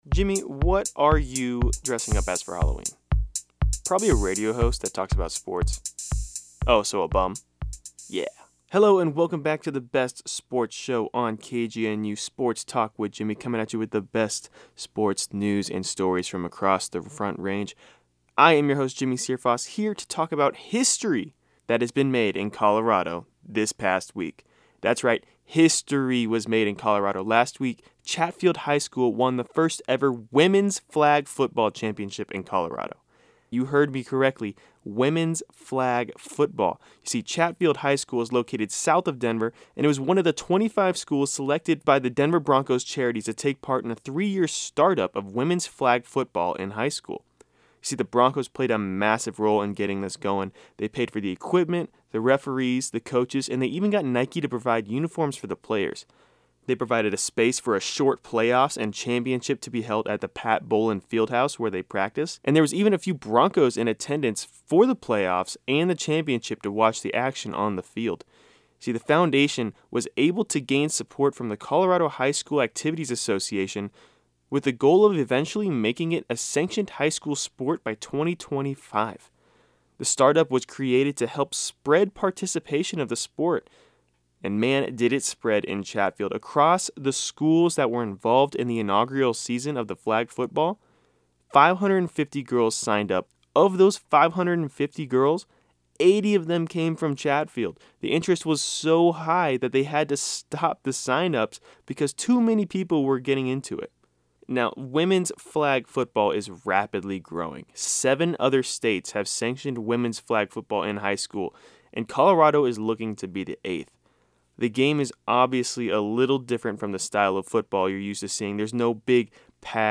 On this week’s regional sports newscast